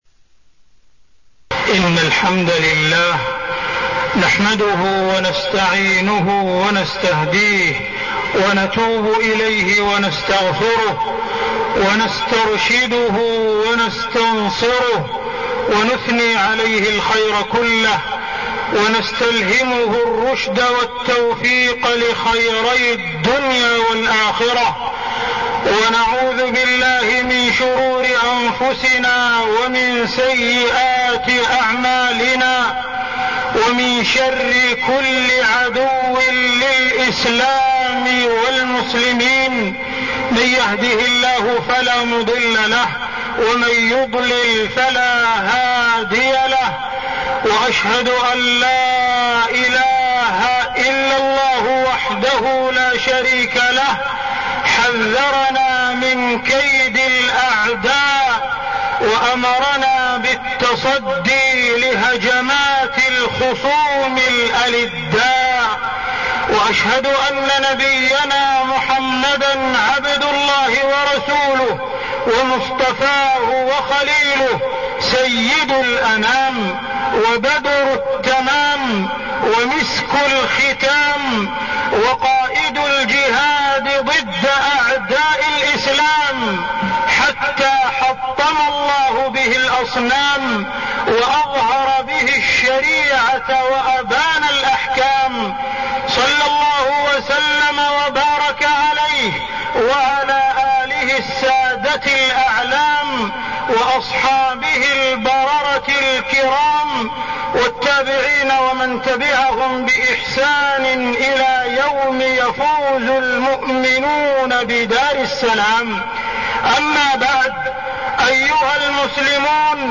تاريخ النشر ٢٣ صفر ١٤١٣ هـ المكان: المسجد الحرام الشيخ: معالي الشيخ أ.د. عبدالرحمن بن عبدالعزيز السديس معالي الشيخ أ.د. عبدالرحمن بن عبدالعزيز السديس الغزو الفكري